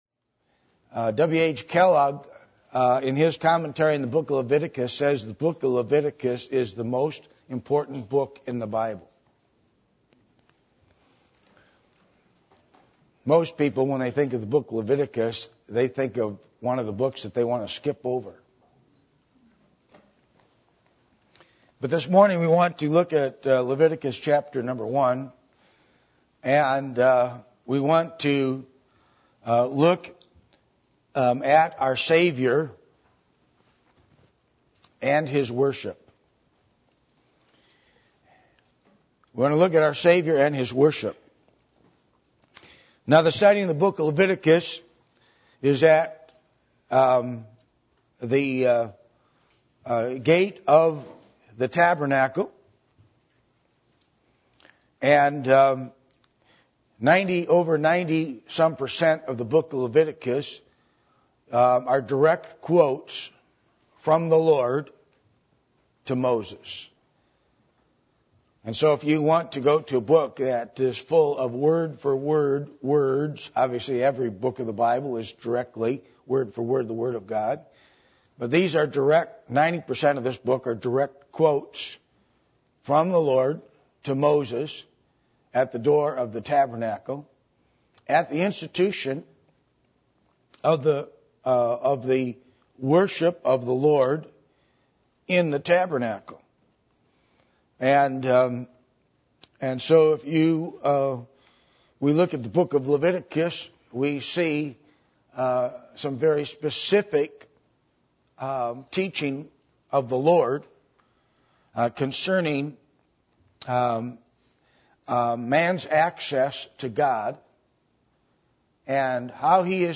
Passage: Leviticus 1:1-17 Service Type: Sunday Morning